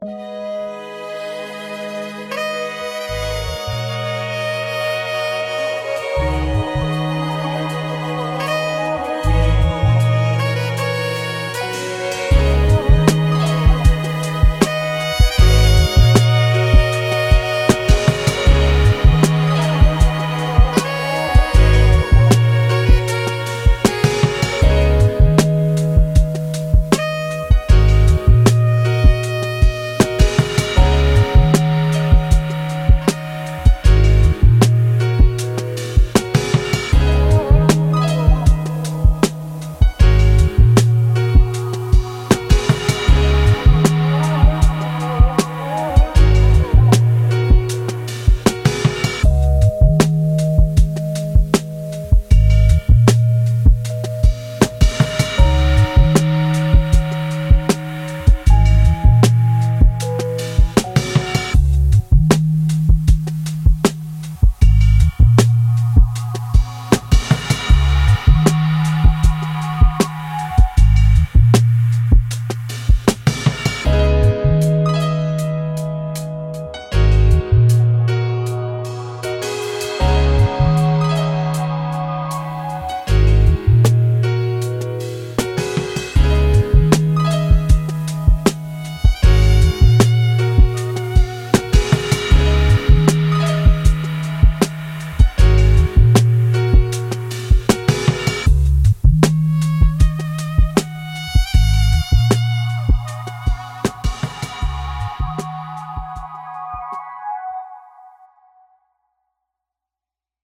Smooth, smokey jazz filled room...